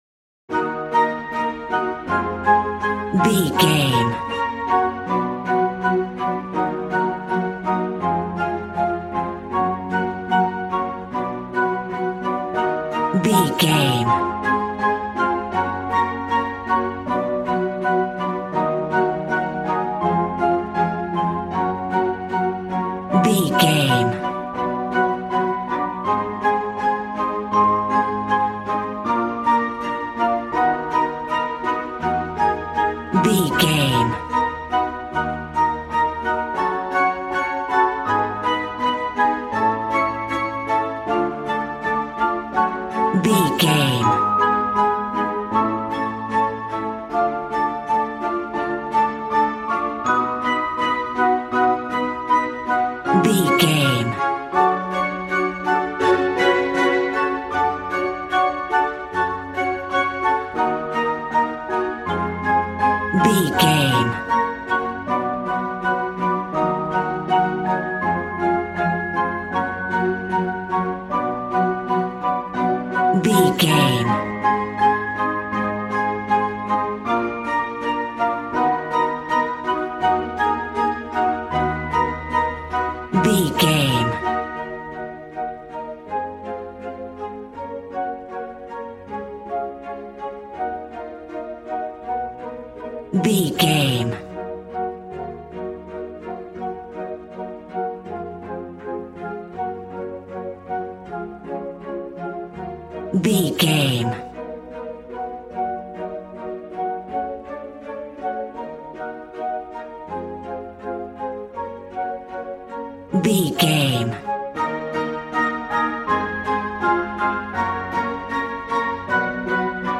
Regal and romantic, a classy piece of classical music.
Ionian/Major
G♭
regal
strings
violin